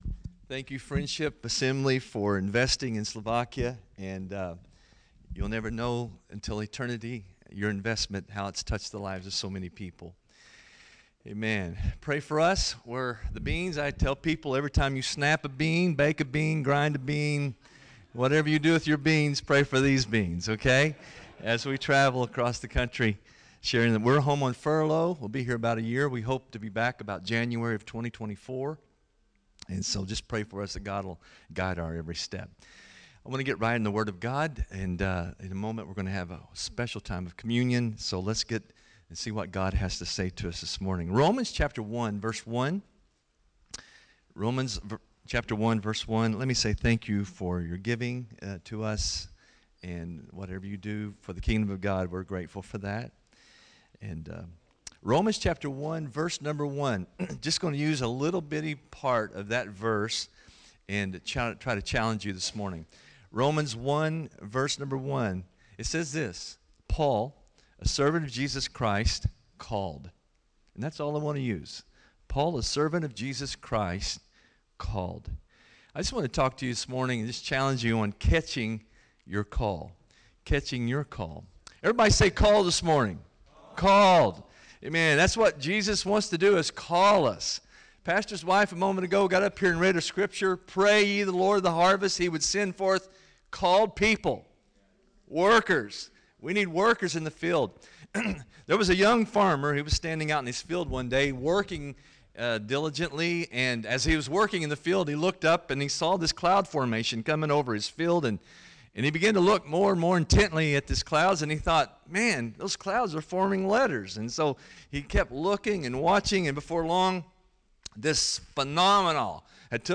Listen in as our missionary guests share about catching the call of God.